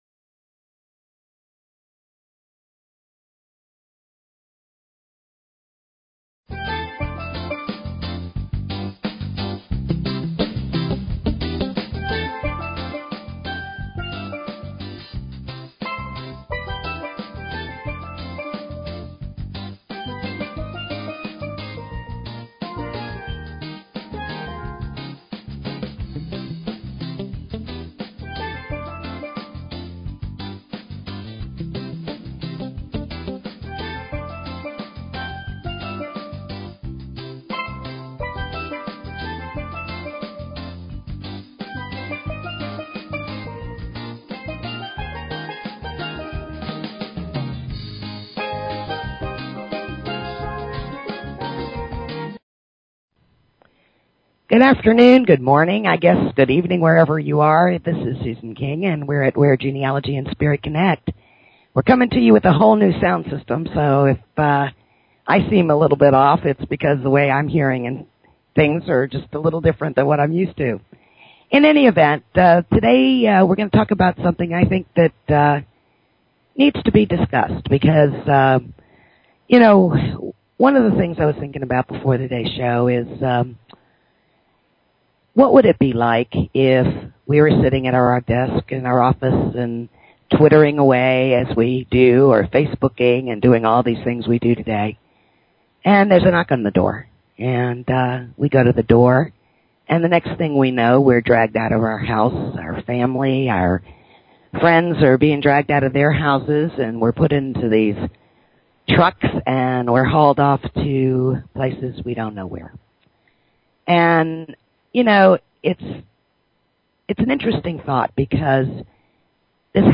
Talk Show Episode, Audio Podcast, Where_Genealogy_and_Spirit_Connect and Courtesy of BBS Radio on , show guests , about , categorized as